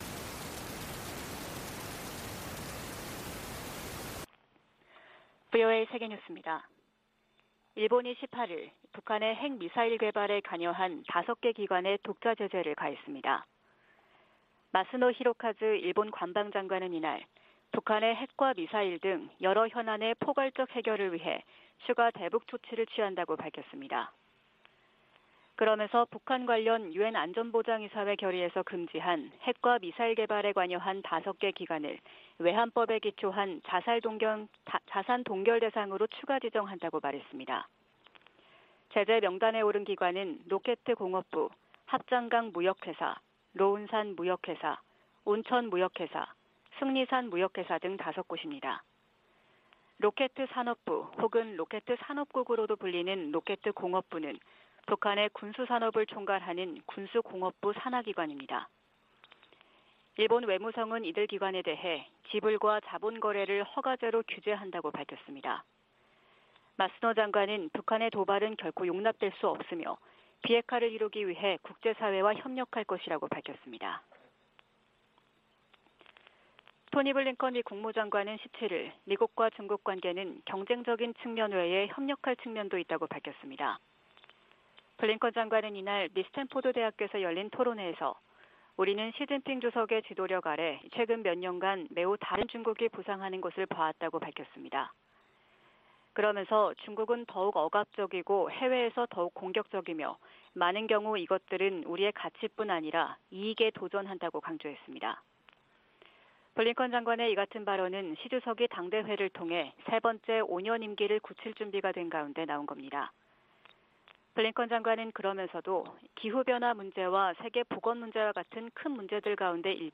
VOA 한국어 '출발 뉴스 쇼', 2022년 10월 19일 방송입니다. 북한의 잇따른 도발은 무시당하지 않겠다는 의지와 강화된 미한일 안보 협력에 대한 반발에서 비롯됐다고 토니 블링컨 미 국무장관이 지적했습니다. 필립 골드버그 주한 미국대사는 전술핵 한반도 재배치론에 부정적 입장을 분명히 했습니다. 북한의 잇단 미사일 발사로 긴장이 고조되면서 일본 내 군사력 증강 여론이 강화되고 있다고 미국의 일본 전문가들이 지적했습니다.